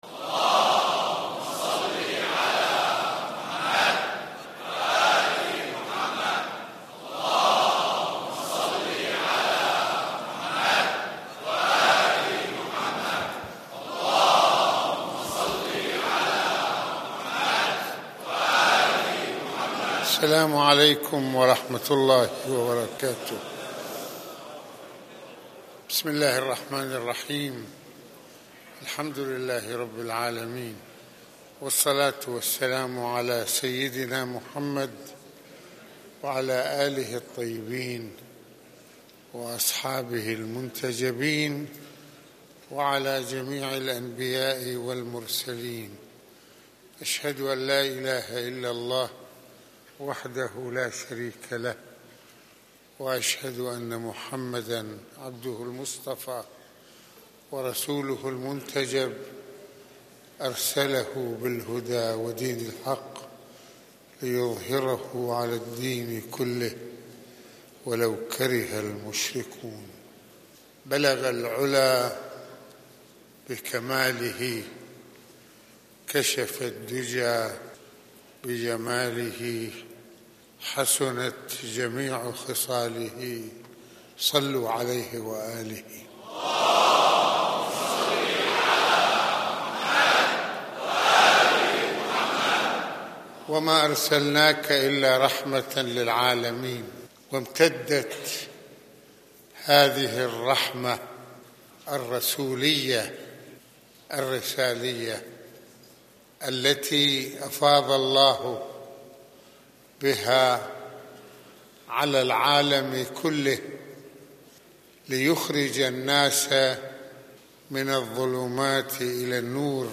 المناسبة: خطبة الجمعة المكان: مسجد الإمامين الحسنين (ع)